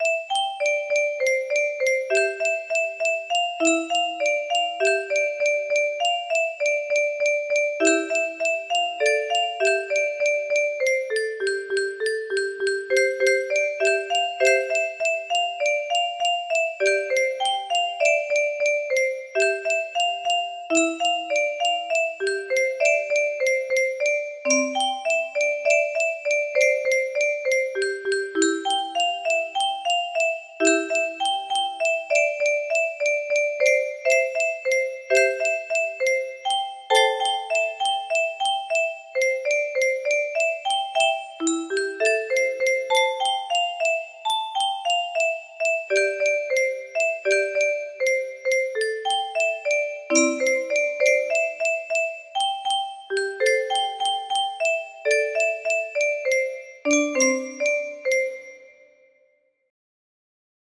Clone of Leo shorter music box melody